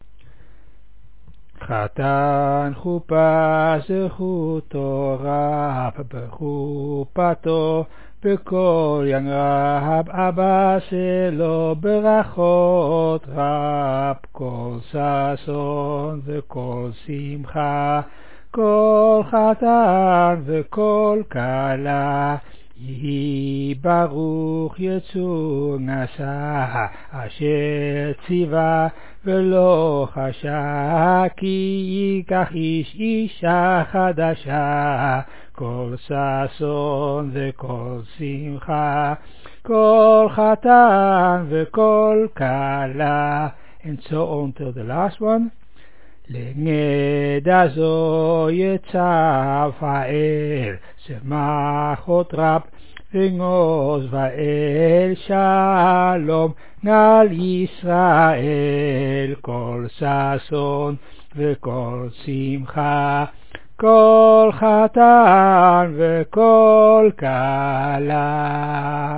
At the end we sing the special Piyut for the Chatan (groom) פיוט לחתן.